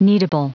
Prononciation du mot kneadable en anglais (fichier audio)
Prononciation du mot : kneadable
kneadable.wav